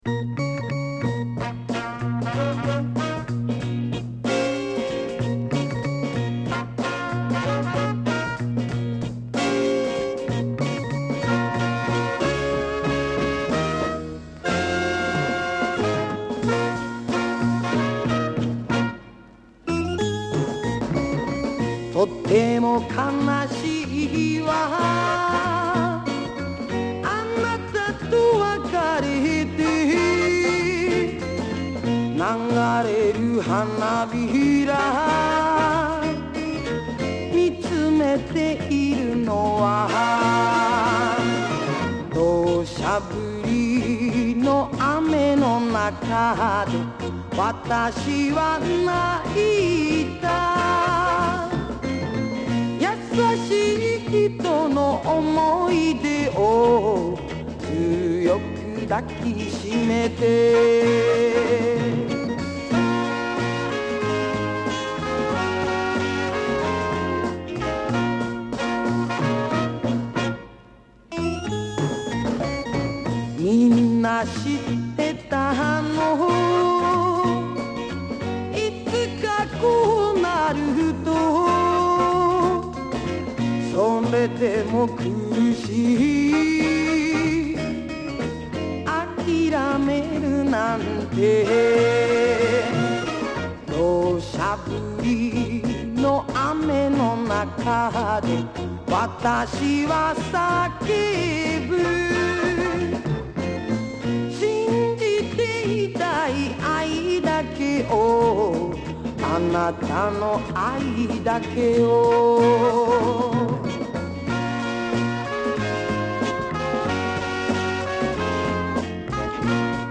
盤に薄いスリキズ有/音の薄い部分で若干チリノイズ有
DJプレイ可能な和モノ作品の多い和製リズム・アンド・ブルーズ歌謡女王